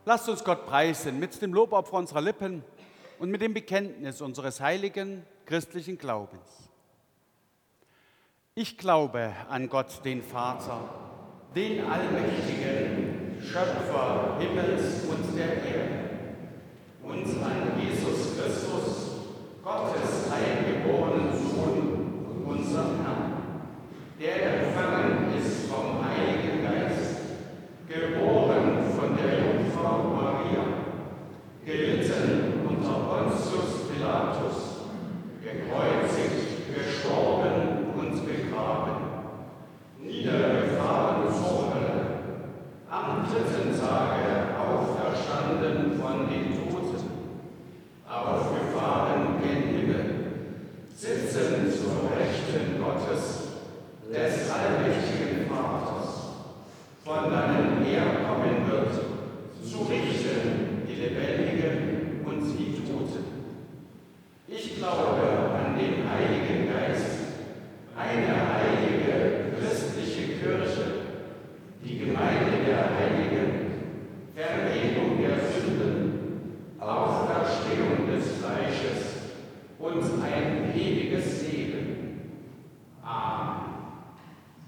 Gottesdienst am 23.02.2025